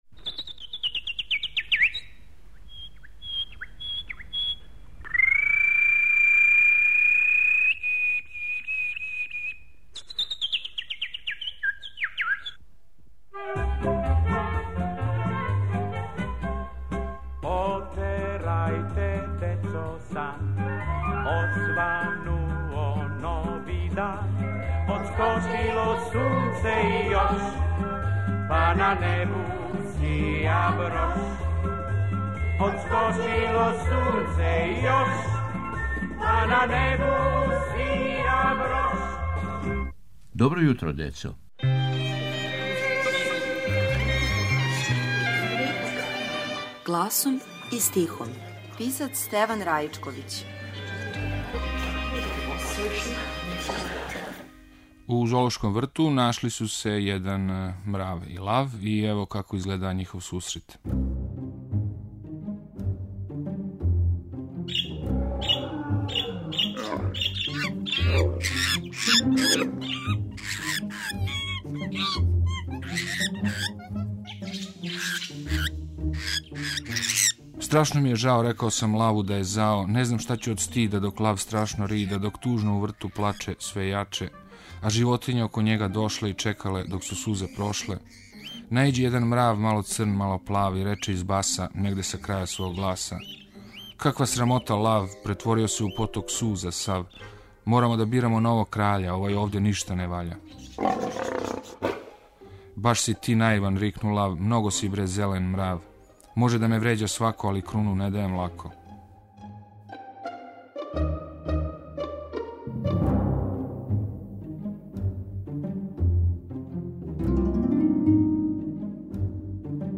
У новом серијалу под именом "Гласом и стихом" - из старе фиоке архиве Радио Београда, гласом и стихом, јављају се чувени песници за децу. Ово је јединствена прилика за слушаоце јер су у питању аутентични звучни записи које само Радио Београд чува у својој архиви. Ове недеље - књижевник Стеван Раичковић.